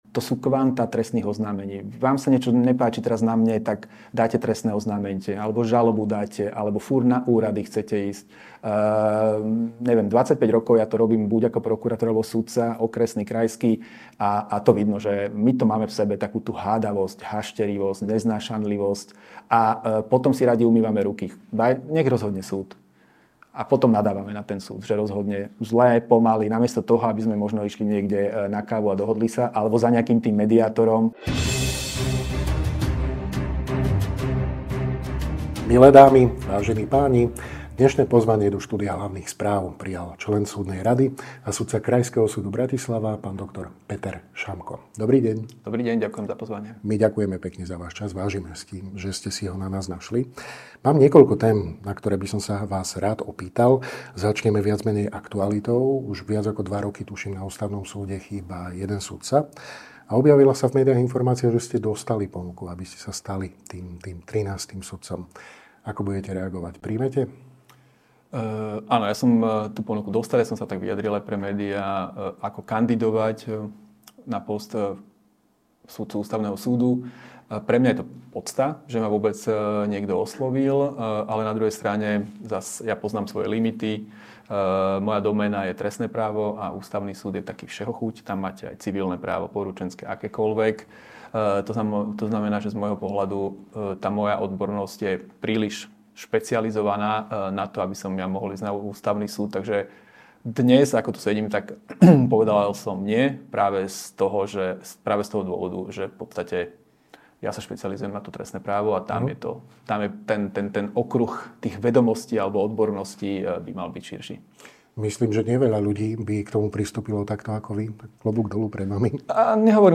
Sudca krajského súdu v Bratislave a člen Súdnej rady, JUDr. Peter Šamko v rozhovore pre Hlavné správy hodnotí fungovanie slovenskej justície